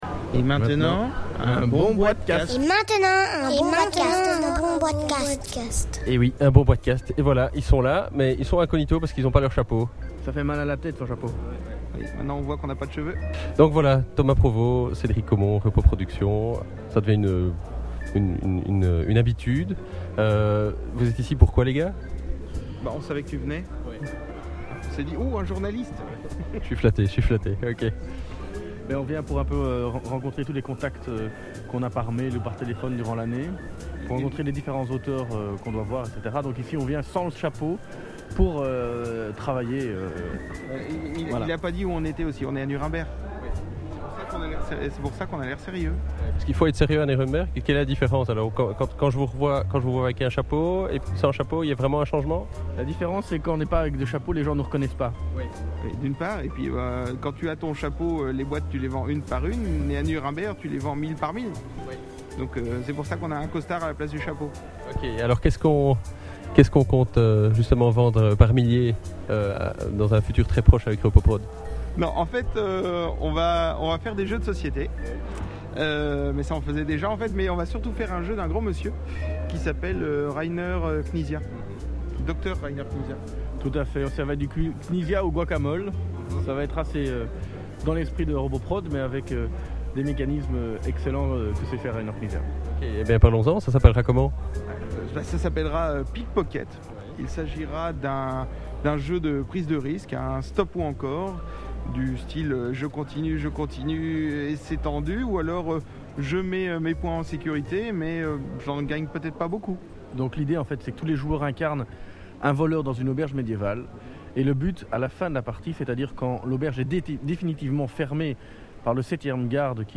Rencontrés lors de la Nuremberg Toy Fair, Les belgos-mexicains de soulèvent le voile de leur prochaines sorties. Tout d’abord une jeu du docteur « Pickpocket » dans lequel vous allez incarner des vides-bourses, des tire-laines et autres coupe-jarrets dans une auberge.